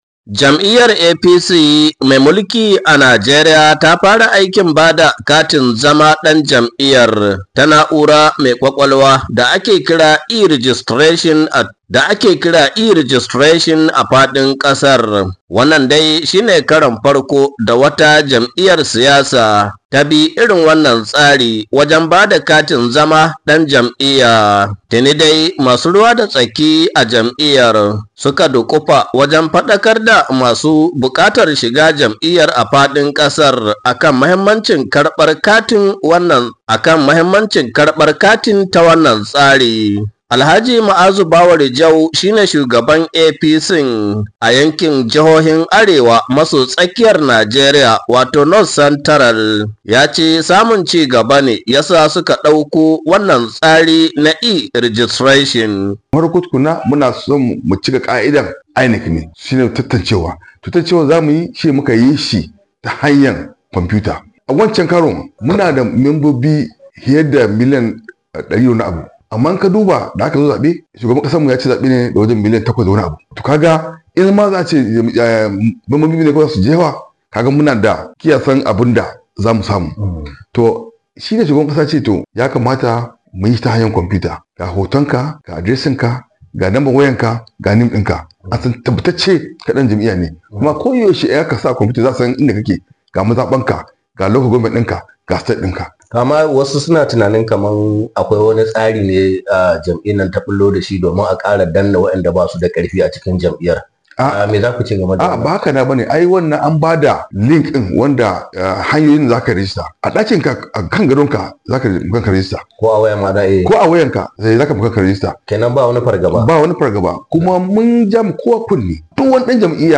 Ga Cikekken Rahoton
Daga Minna jihar Neja Najeriya